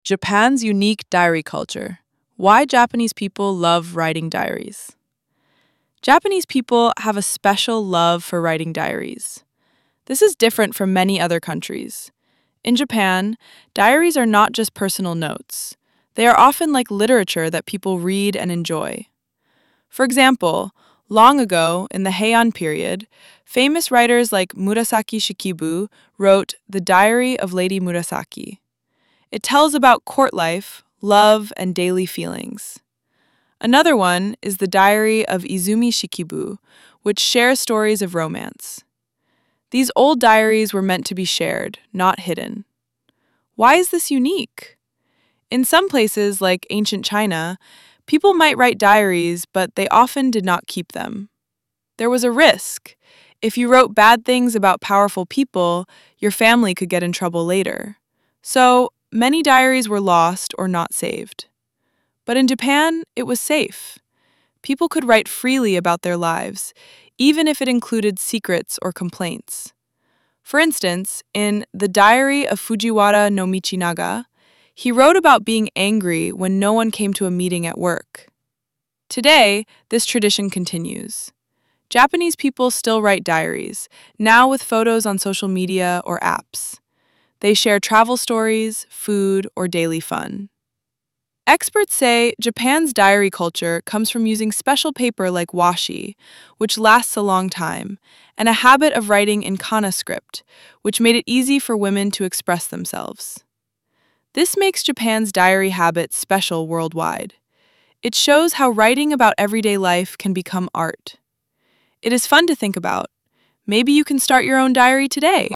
＜音読用音声＞